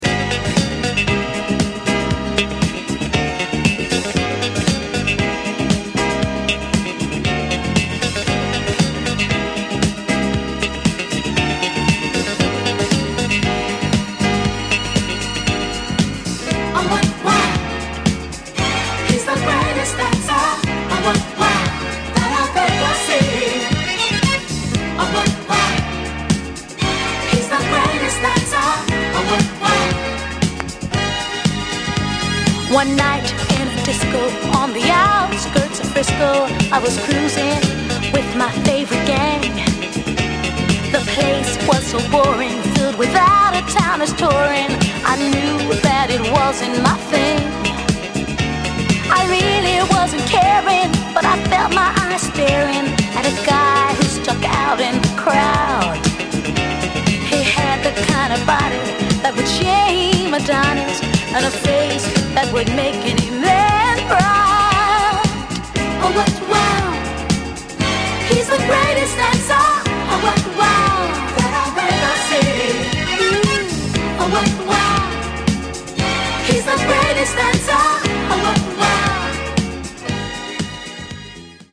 Disco anthem!
in perfect harmony